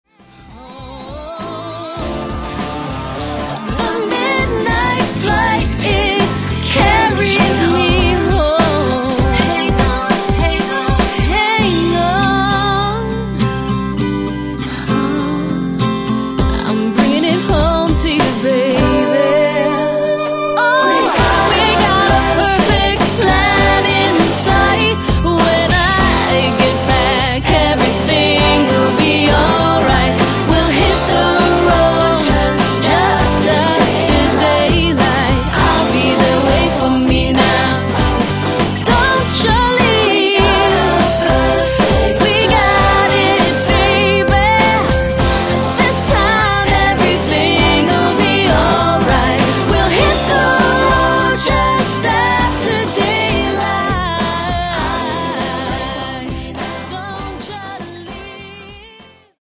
pop/r&b
contemporary sounds of guitar-based pop rock with
smooth and sexy r&b rhythms and reggae-tinged